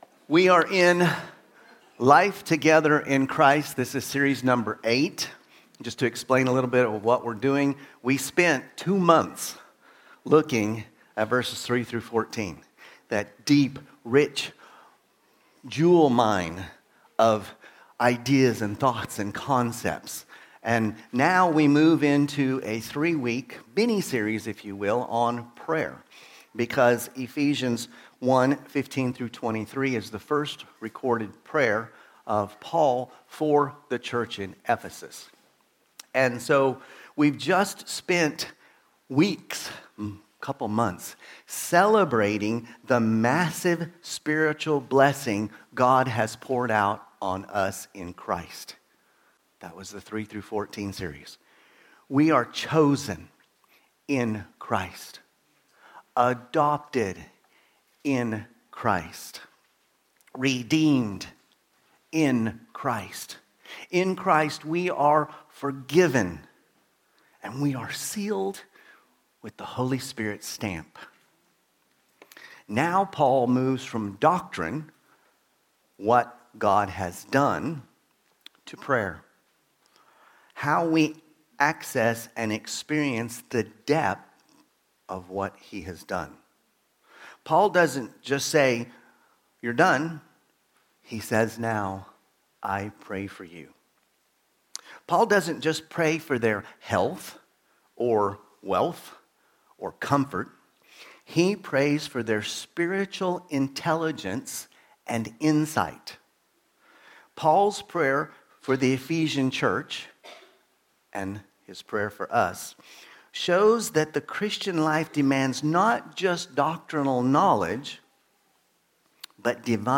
Sermons – Immanuel Baptist Church | Madrid
From Series: "Sunday Service"